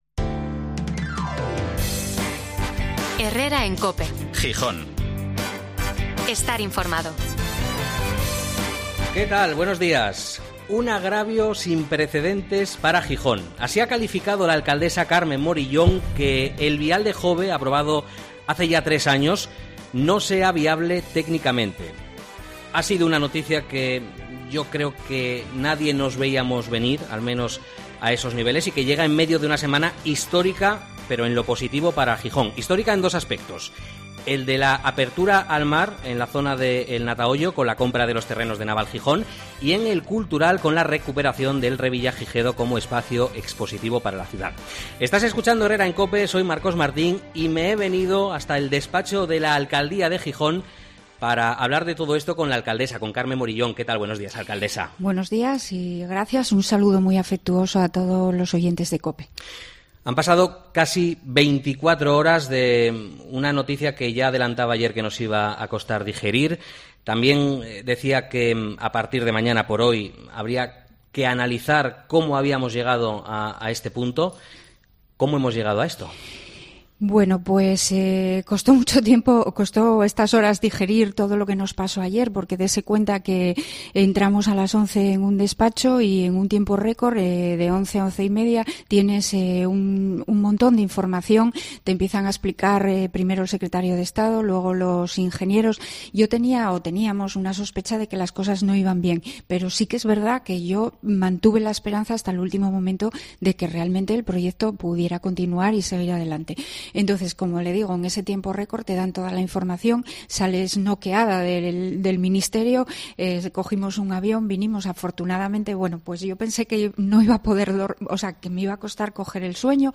Entrevista a la alcaldesa de Gijón, Carmen Moriyón, en COPE